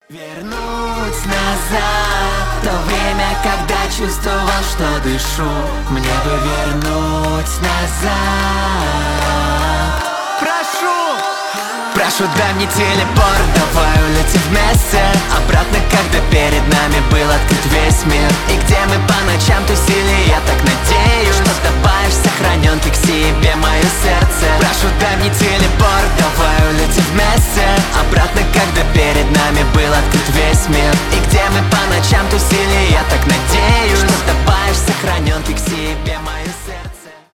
поп , альтернатива , рок